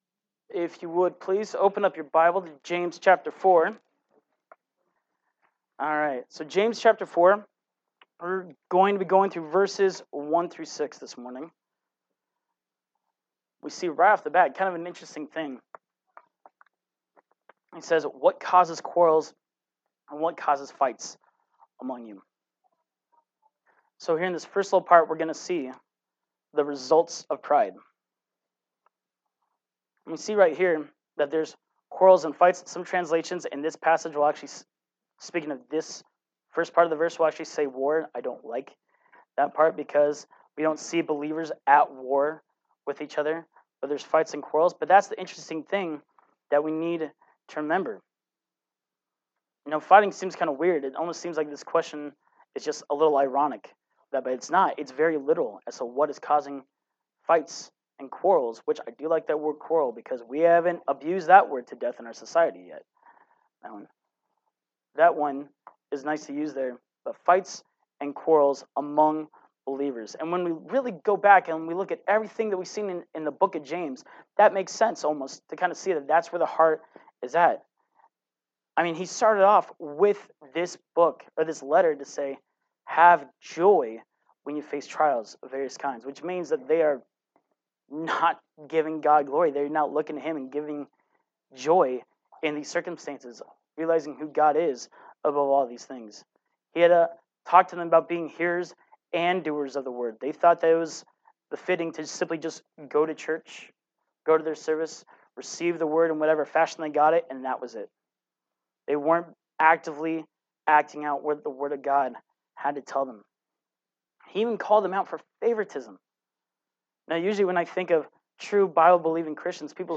Note: Audio is missing about five minutes near the beginning of the message, including the scripture reading and prayer. Silence has been edited out for smoother listening.
Service Type: Sunday Morning Worship